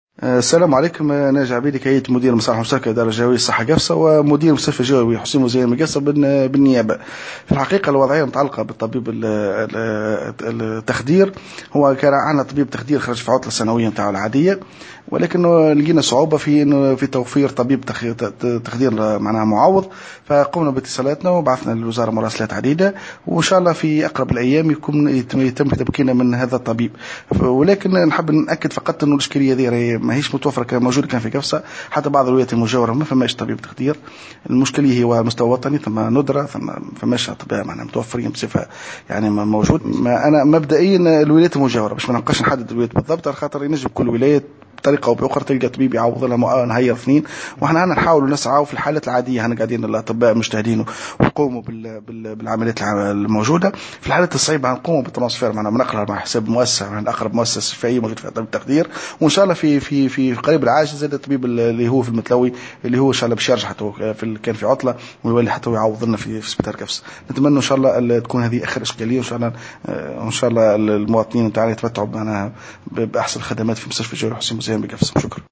تصريح